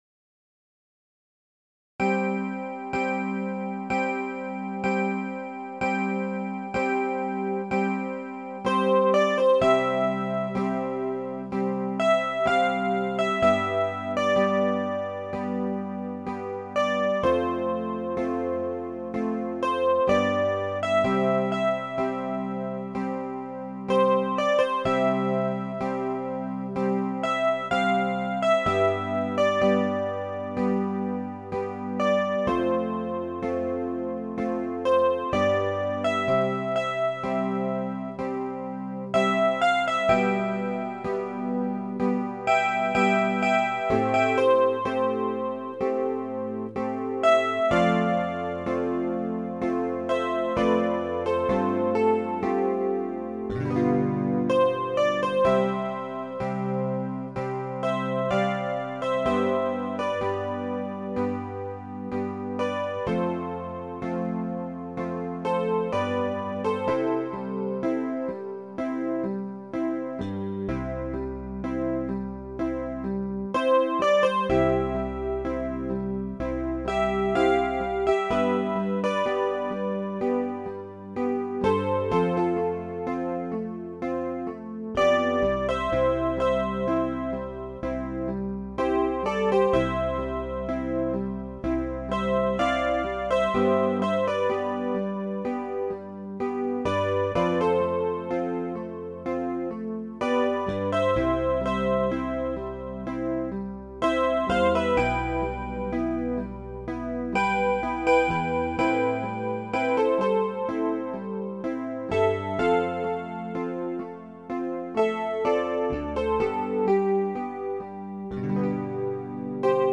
詩の朗読にギターを用いることはよくありますが、合奏に朗読を合わせるのははじめてで、不安でしたが演奏は大成功でした。